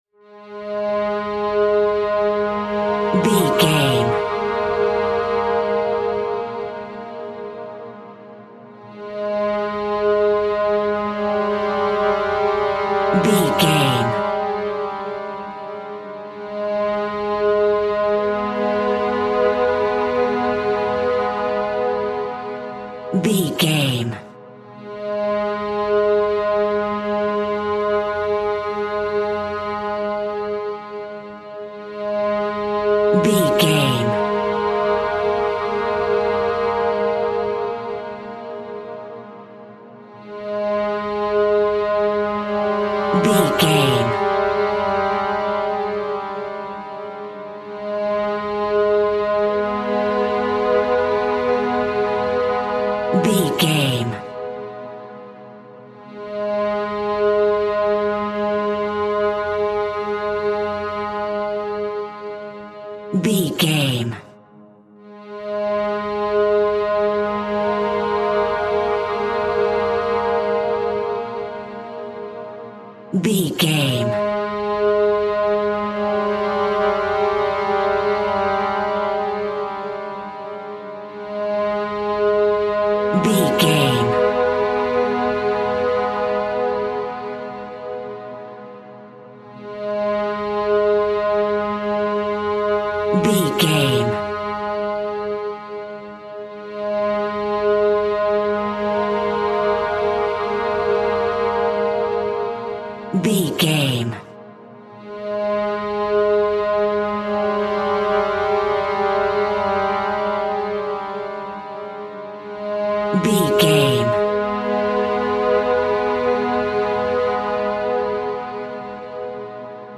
Scary String Theme.
Aeolian/Minor
A♭
ominous
haunting
eerie
viola